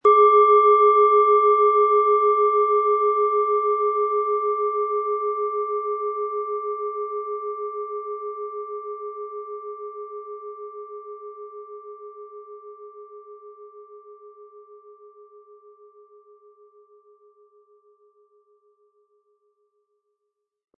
Wie klingt diese tibetische Klangschale mit dem Planetenton Uranus?
Wir haben versucht den Ton so authentisch wie machbar aufzunehmen, damit Sie gut wahrnehmen können, wie die Klangschale klingen wird.
Durch die traditionsreiche Fertigung hat die Schale vielmehr diesen kraftvollen Ton und das tiefe, innere Berühren der traditionellen Handarbeit
MaterialBronze